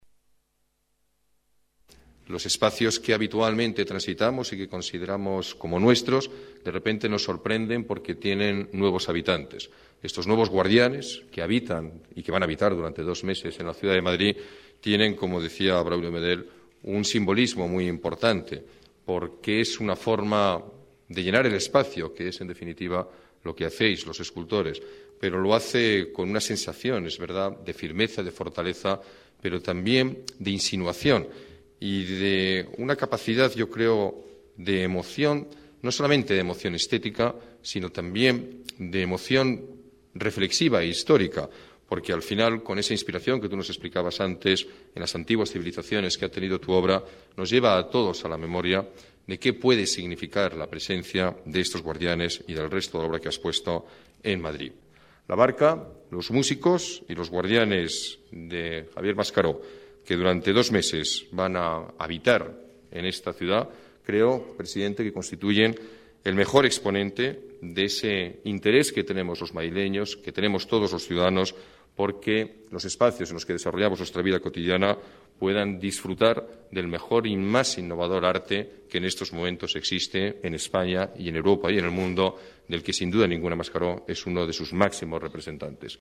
Nueva ventana:Declaraciones del alcalde, Alberto Ruiz-Gallardón